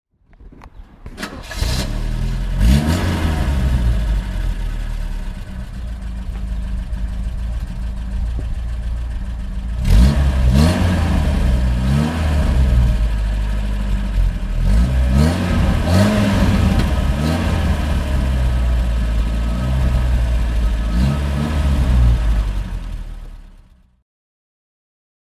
Opel Ascona 19 SR (1974) - Starten und Leerlauf